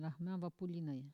Locution ( parler, expression, langue,... )